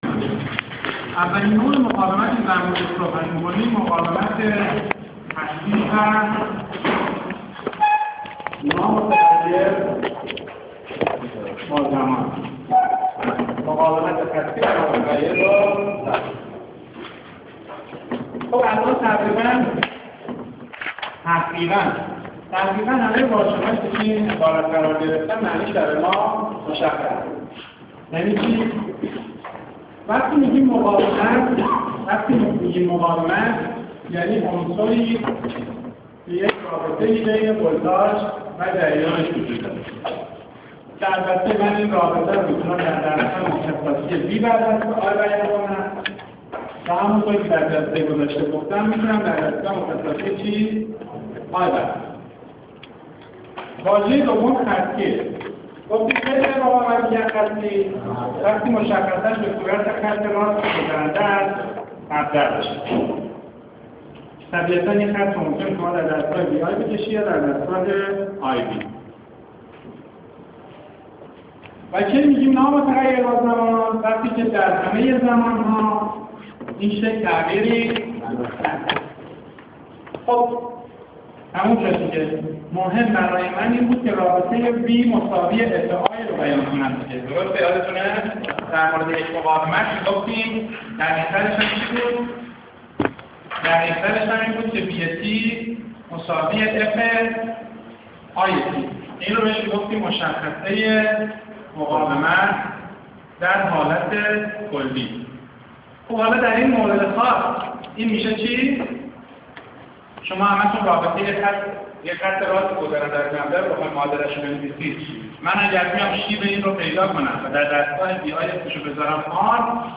تدریس صوتی درس مدارالکتریکی 1